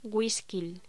Locución: Güisquil